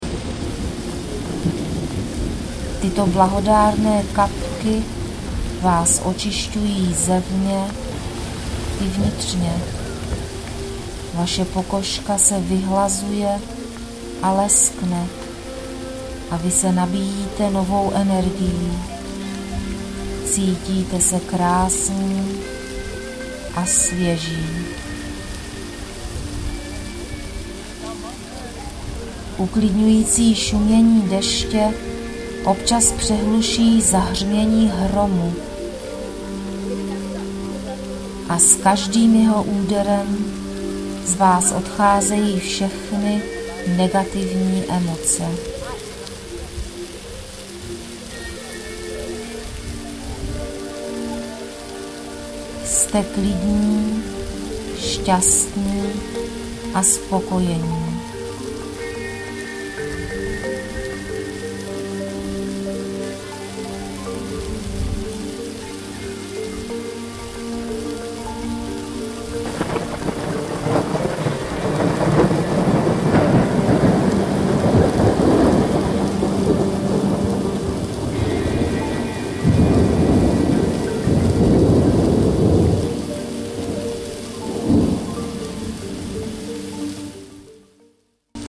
Z tohoto důvodu jsem pro Vás vytvořila relaxační CD.
Ukázka africké relaxace.mp3